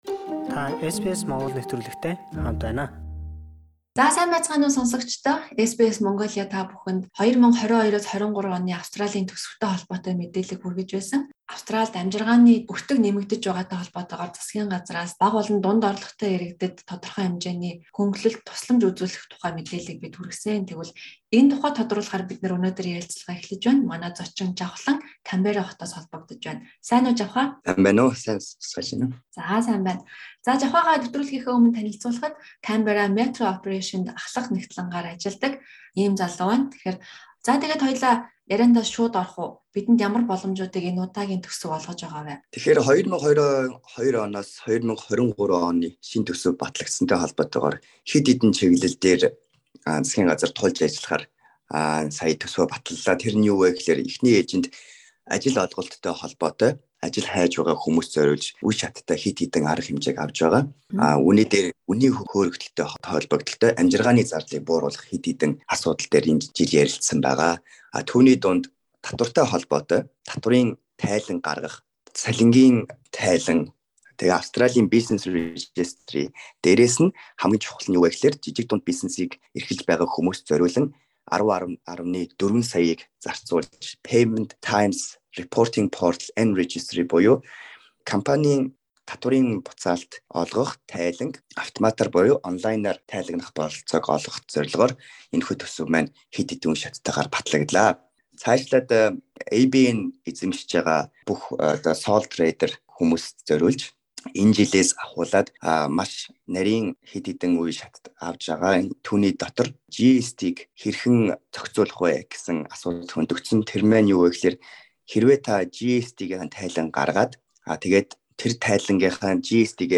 ярилцлаа